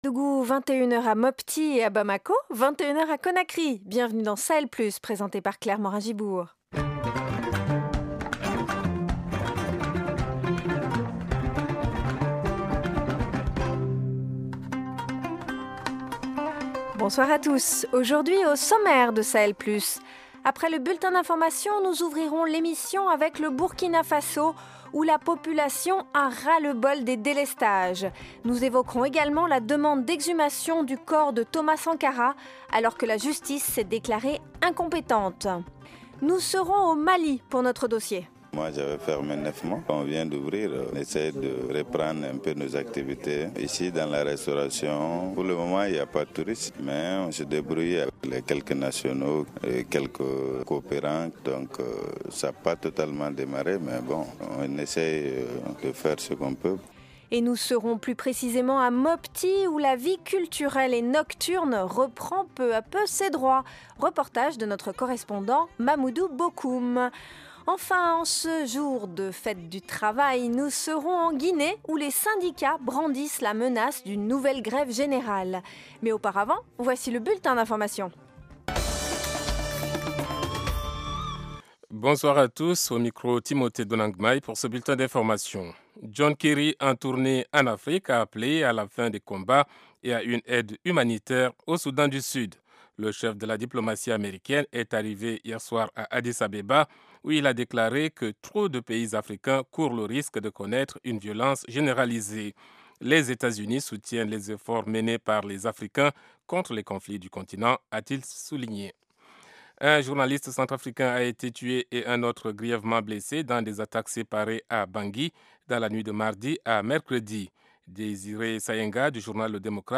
Dossier : à Mopti au Mali, la vie culturelle et nocturne reprend peu à peu ses droits. Reportage